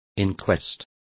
Complete with pronunciation of the translation of inquests.